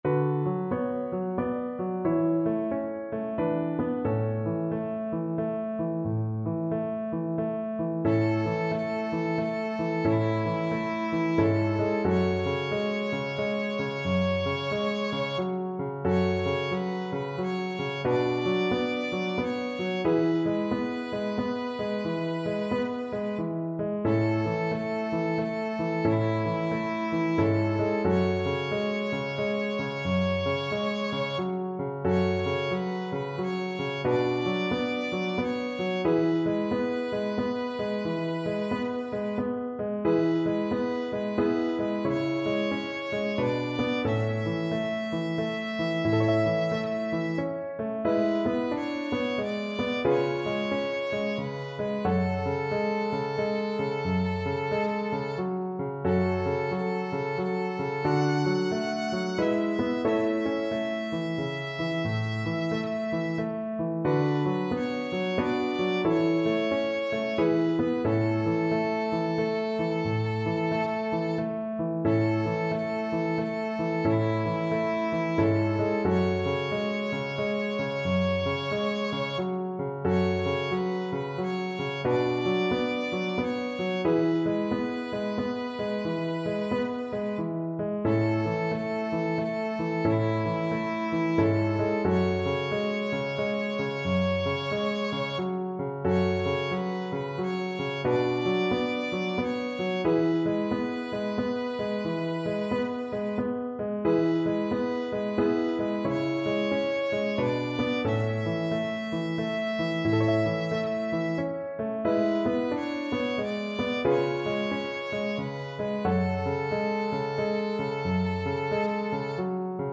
Violin
A major (Sounding Pitch) (View more A major Music for Violin )
=90 Andante, gentle swing
3/4 (View more 3/4 Music)
un_canadien_errant_VLN.mp3